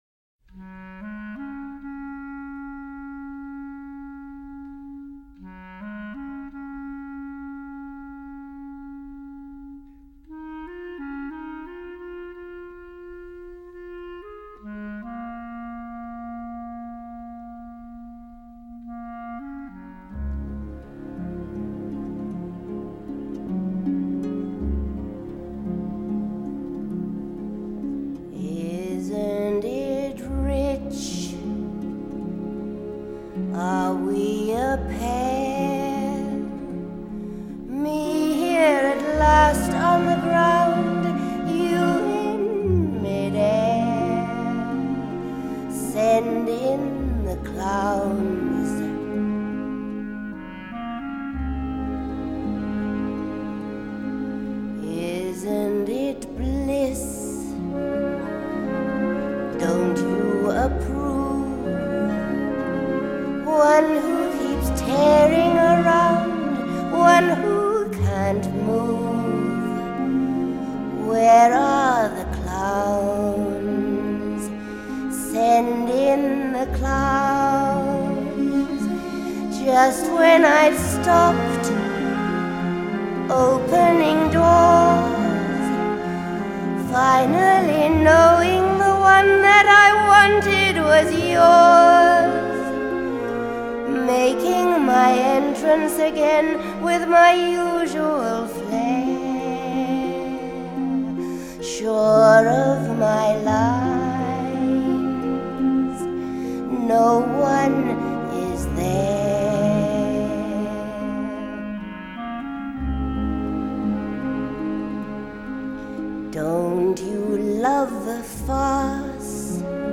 我当初保留了其中动听的独唱歌曲，其它一些合唱曲被删去了。